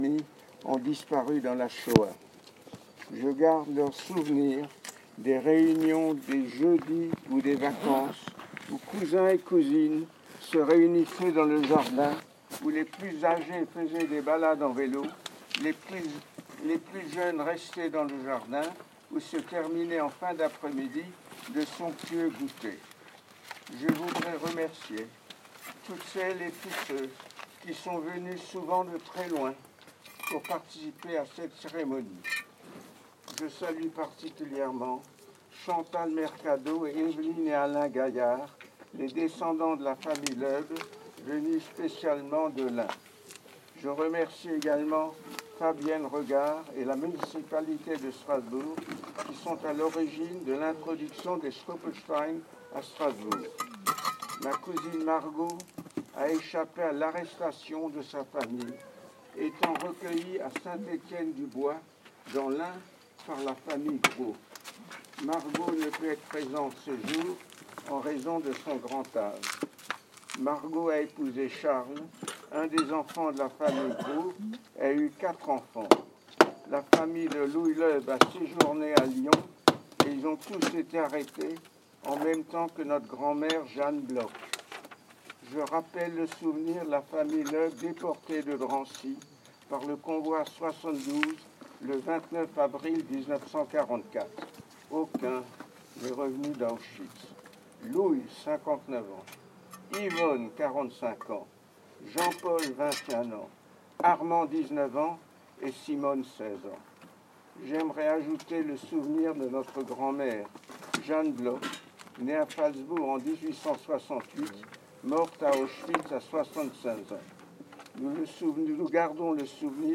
Vous pouvez entendre un enregistrement de cette cérémonie (contrôle-cliquez ou cliquez avec la touche droite sur les liens pour sauvegarder le fichier, si votre navigateur ne peut pas lire le fichier m4a).